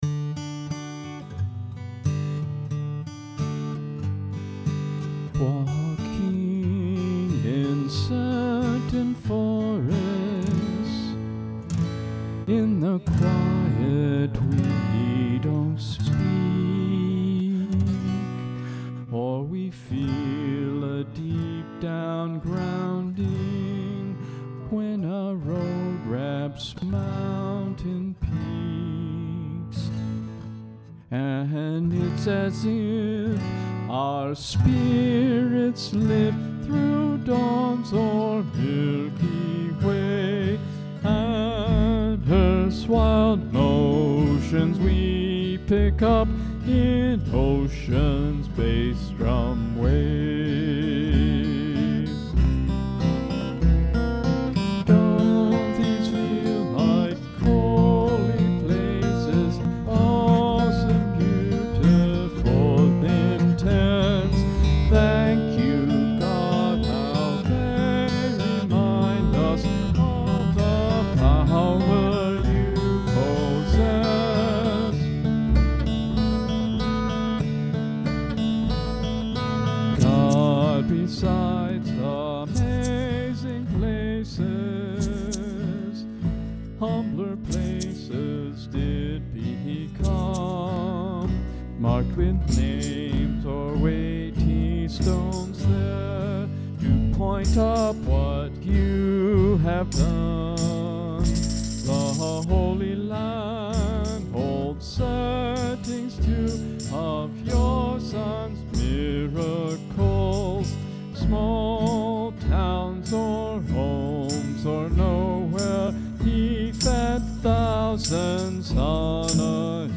Holy Places--a song about God's presence, for praise band
This version is for worship team instruments, although the musical approach is a bit different.
If you have an extra, very capable guitar player (and possibly a "soundman"), we love bringing in some guitar picking to increase the energy in certain spots, replacing the conga.